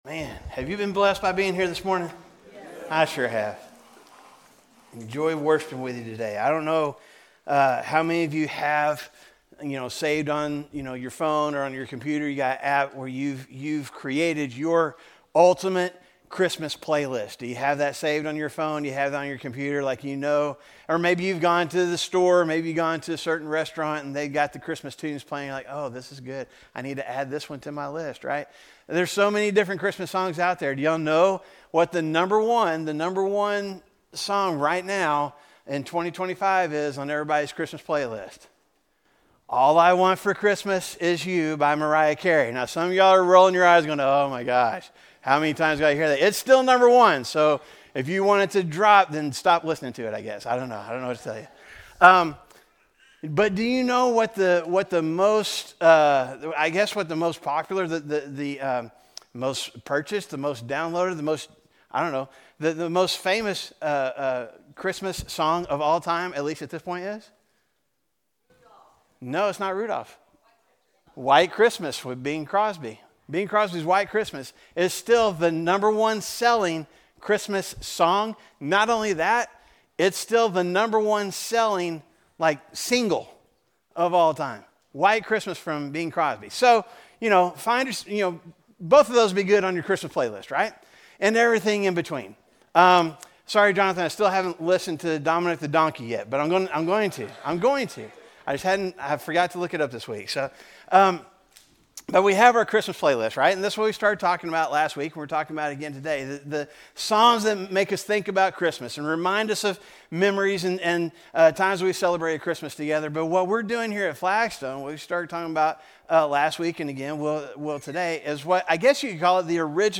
A message from the series "Christmas Playlist."